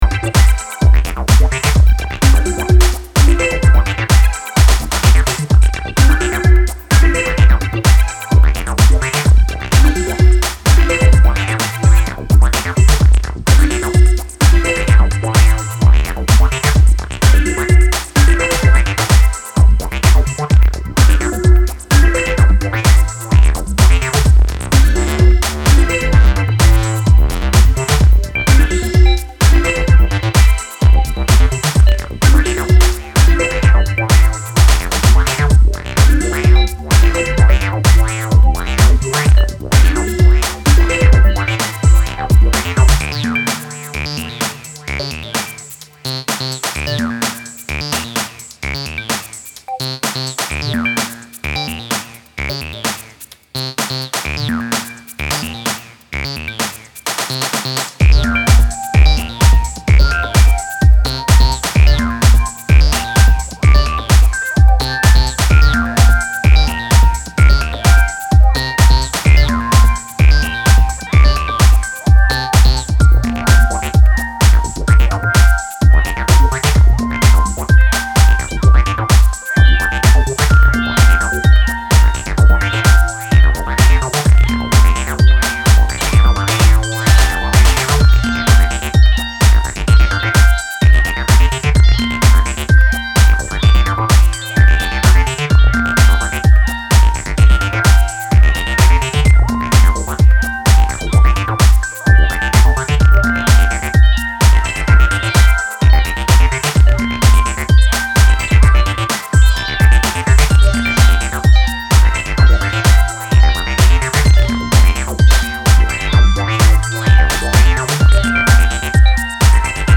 electro, italo-disco and acid infected floor burners!